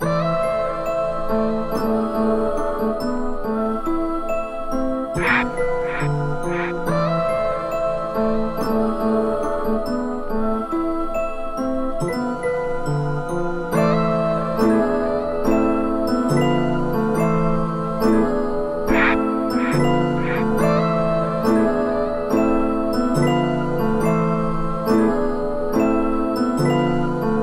标记树（风铃）的声音" 标记树高高在上
描述：Mark Tree (wind chime) high glissando upA mark tree是一种专门的打击乐器，类似于风铃，但通常是用手弹奏。风铃经过精细的调音，并按音高顺序排列。
录音是用JVC GZMG35U摄录机完成的。
标签： 阴森恐怖 滑音 标记树 神秘 打击乐 风铃
声道立体声